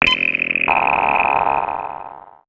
UI_SFX_Pack_61_43.wav